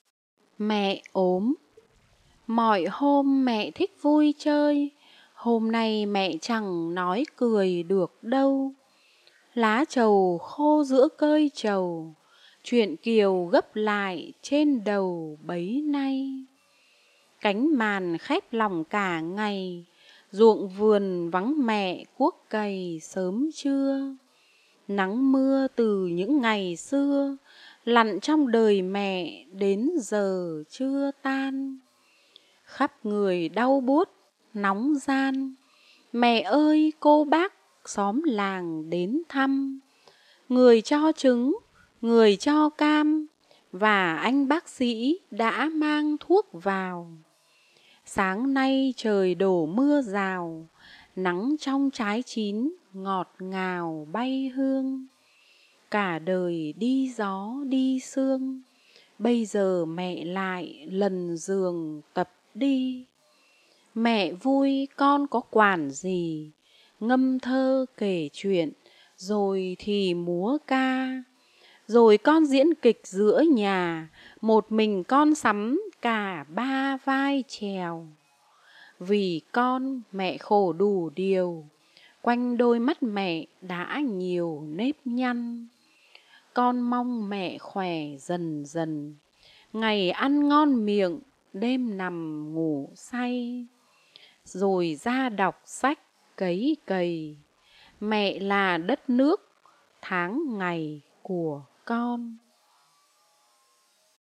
Sách nói | Bài thơ "Mẹ ốm"